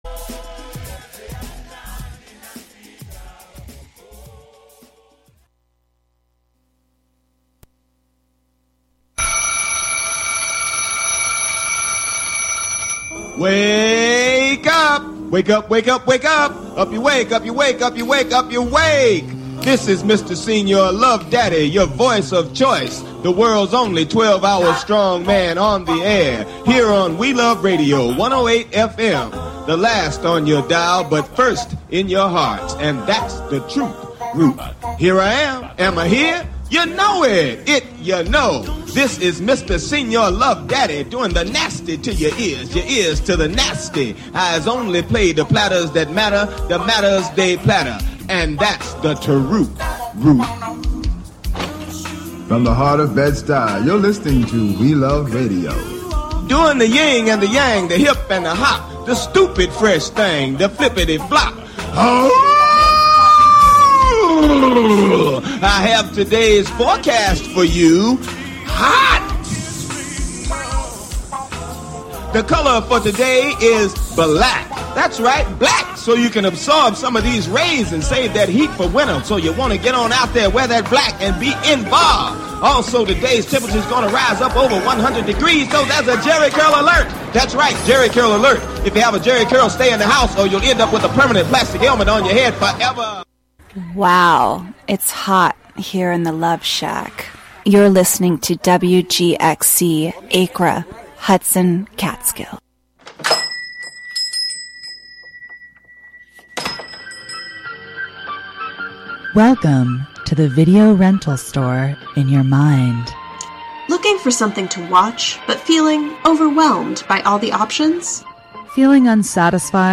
REAL country music